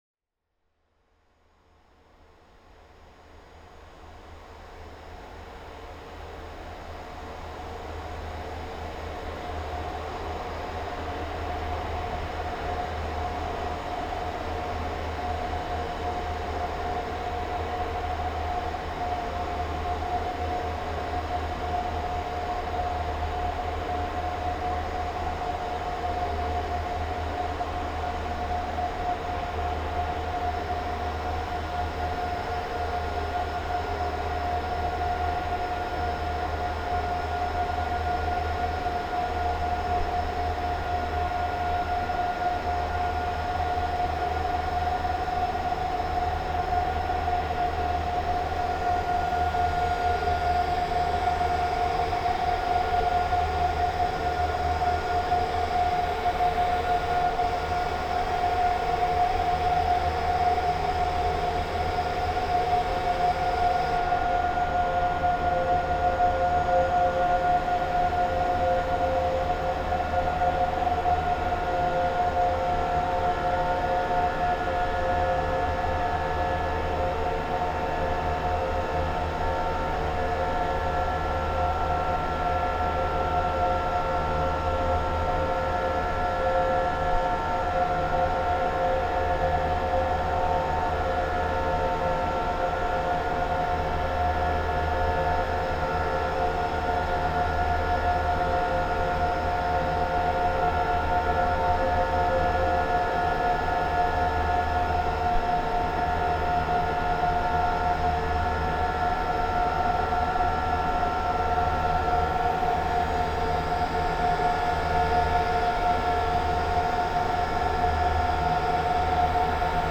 Modular Compositions
Core Symphony (2025,  7:28, stereo)
Samples from the “core” of Binghamton University’s Lecture Hall, an IT centrifuge which connects the 12 lecture halls to university networks, projectors, sound systems, etc. Recordings were focused on mechanical drones and anomalies within the space.